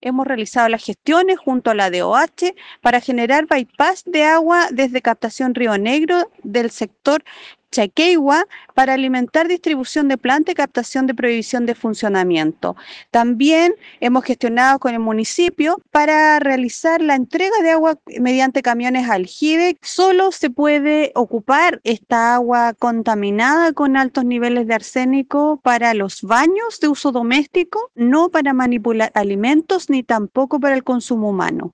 El análisis físico-químico del agua se contrastó con muestras de la Seremi de Salud, apoyando el llamado a los habitantes de dichos sectores, la autoridad, Karin Solís, dejó en claro que se han adoptado medidas mitigatorias para la mayoría de las familias.
cuna-aguamala-seremi.mp3